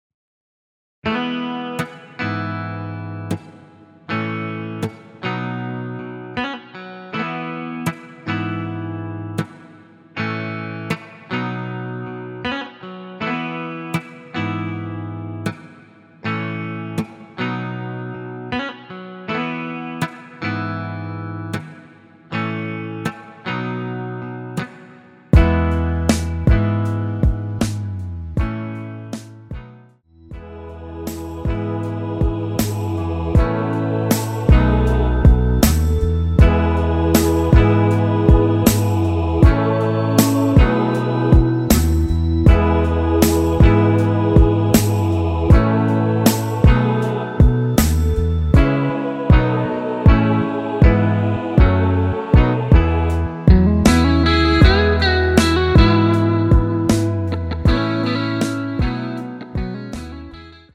원키에서 (+2)올린 멜로디 포함된 MR입니다.
앞부분30초, 뒷부분30초씩 편집해서 올려 드리고 있습니다.
중간에 음이 끈어지고 다시 나오는 이유는